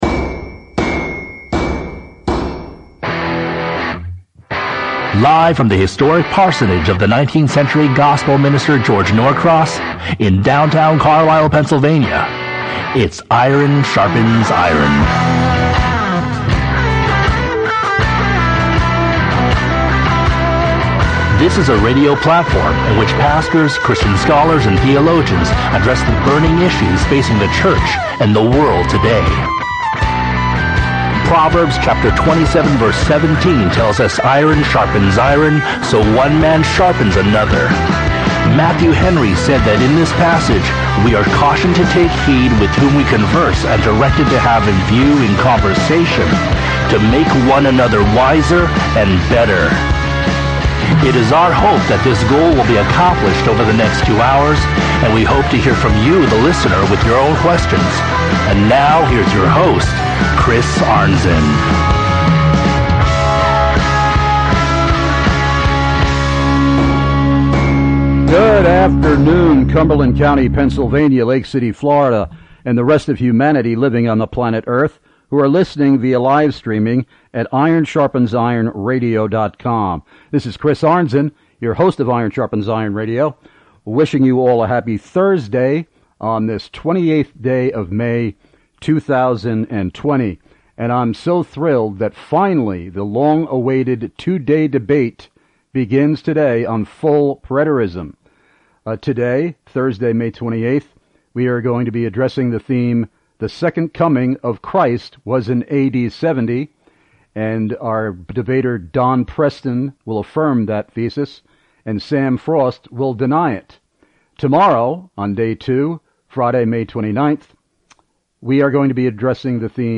2-DAY DEBATE